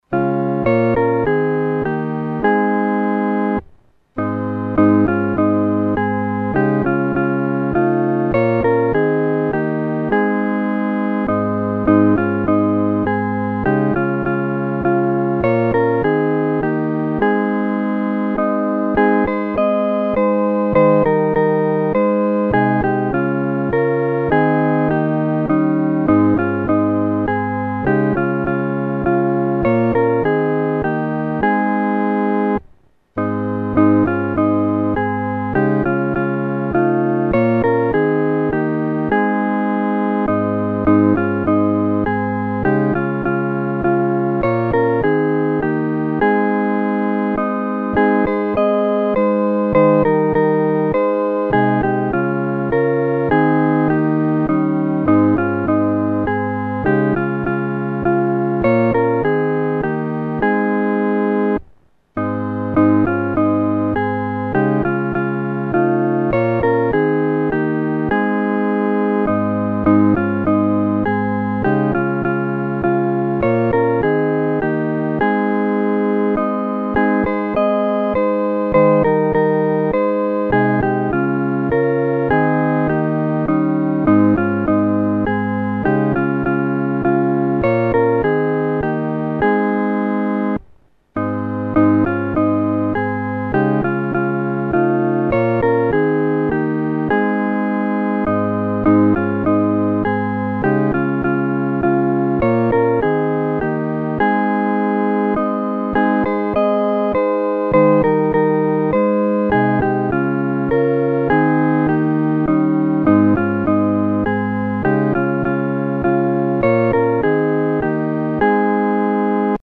合奏（第一声）